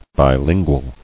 [bi·lin·gual]